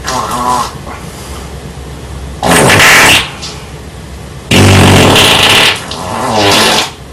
Nuclear Fart Sound Sound Button: Unblocked Meme Soundboard